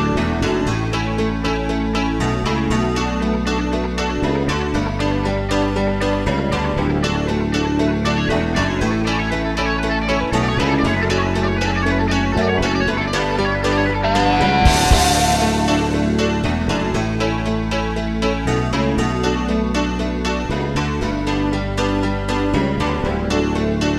Three Semitones Down Rock 4:28 Buy £1.50